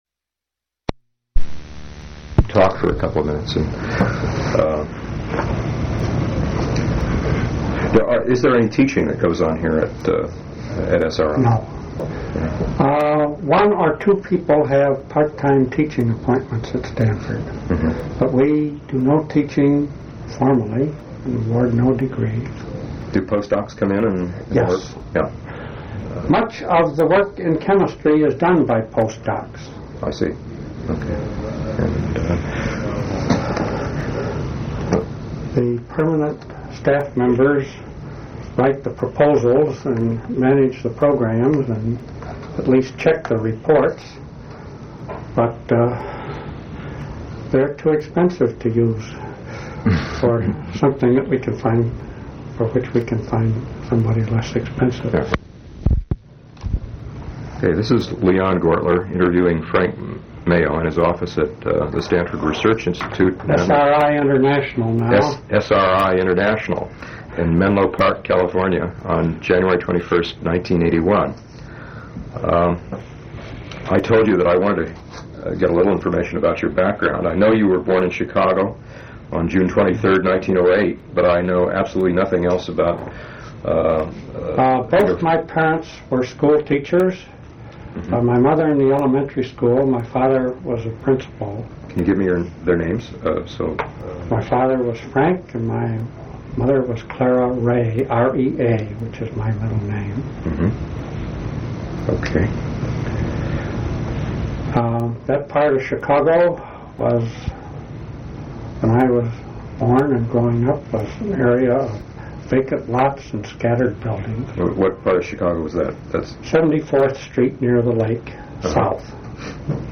Complete transcript of interview